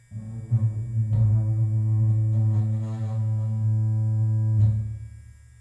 剃刀，打击乐器组 " 剃刀光滑2
描述：电动剃须刀，金属棒，低音弦和金属罐。
Tag: 剃须刀 重复 电机 金属 金属加工 发动机